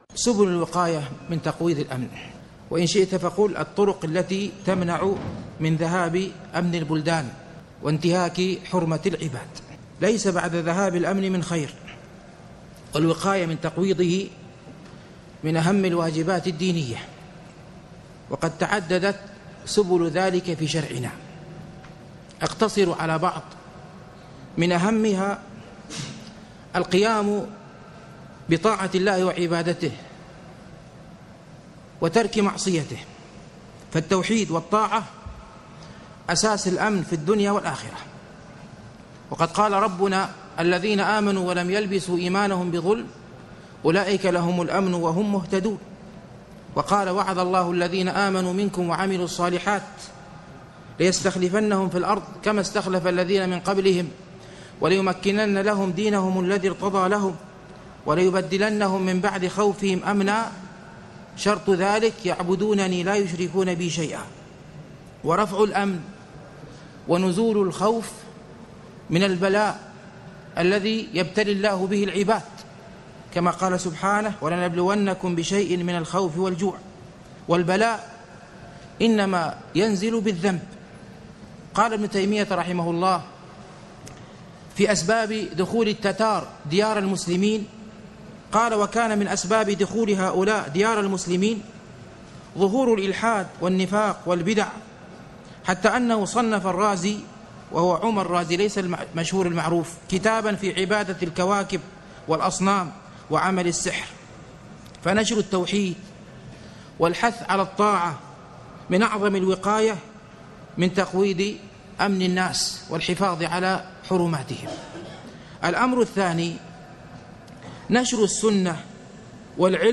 المحاضرات